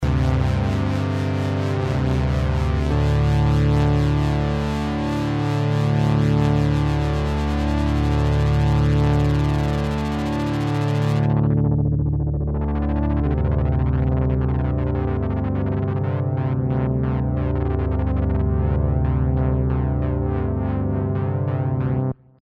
TEST: LFO frequency